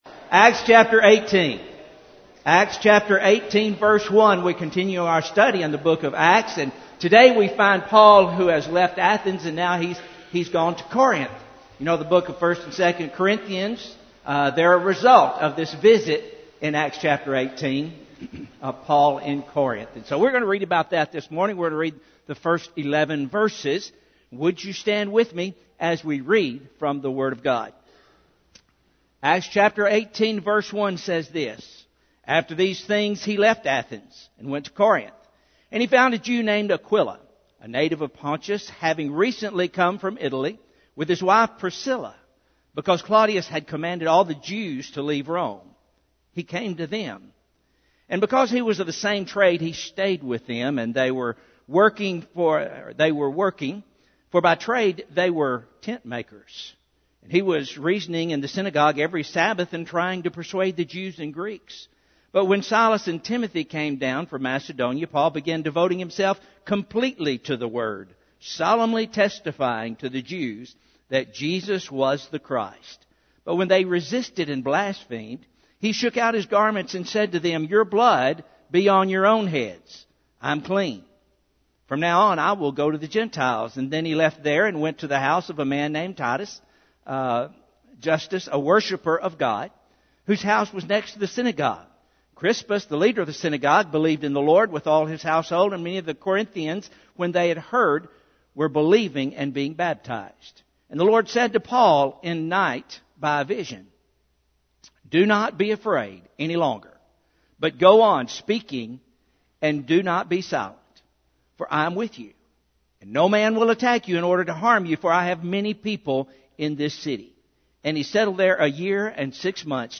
Sermon Series A udio 1.